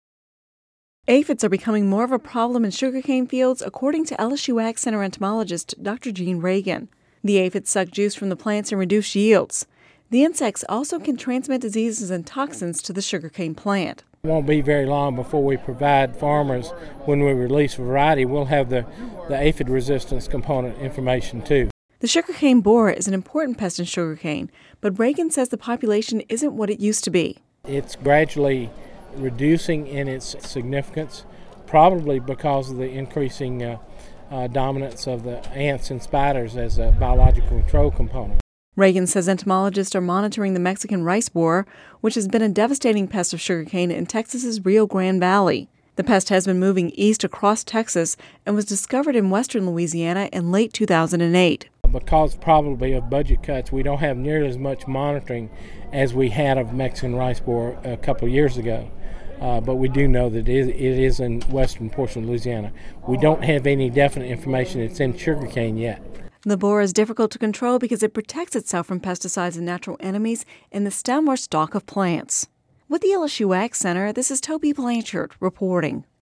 (Radio News 08/02/10)